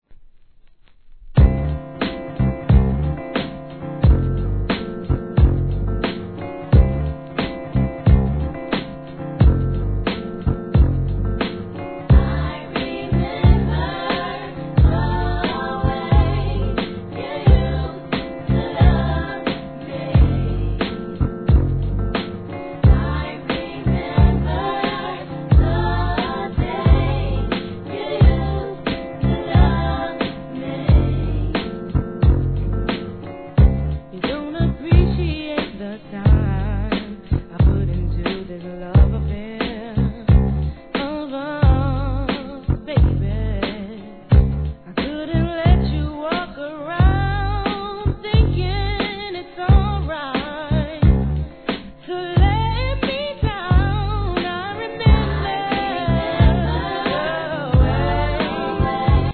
HIP HOP/R&B
彼女の透き通るような歌声で大ヒット!!